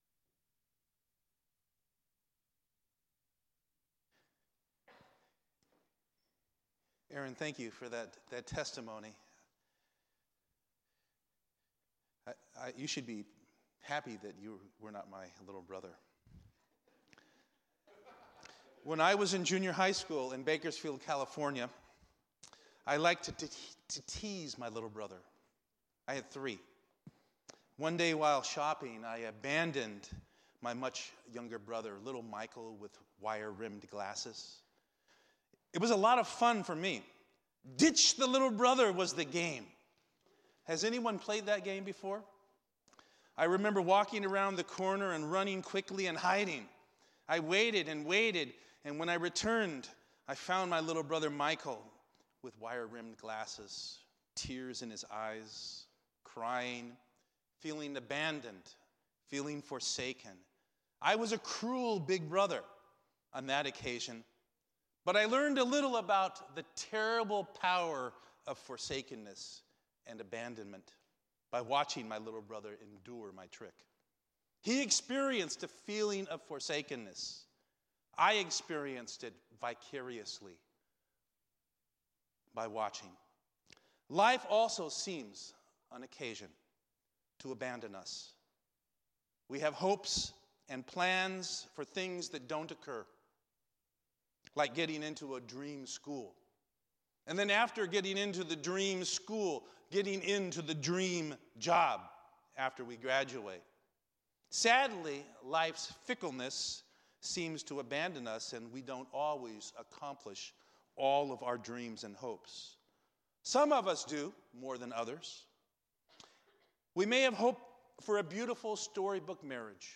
Lenten Series: Last Words